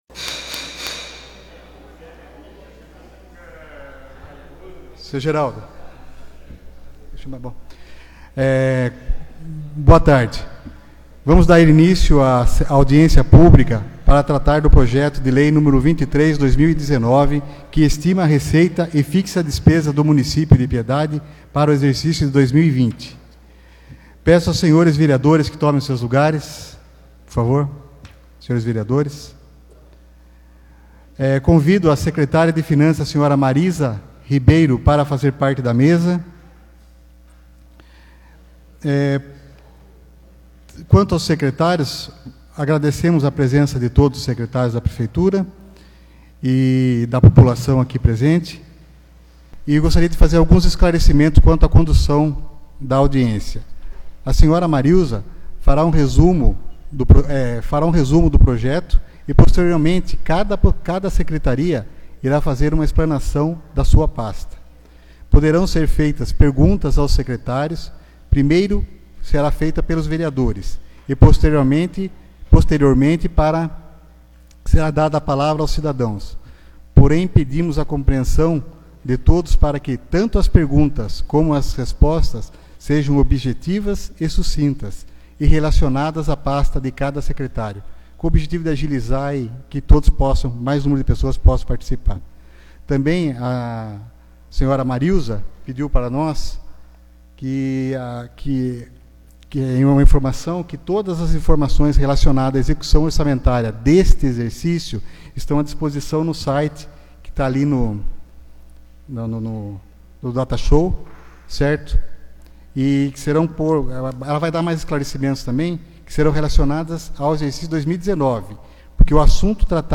Audiência Pública da Lei Orçamentária Anual - LOA - Exercício de 2020.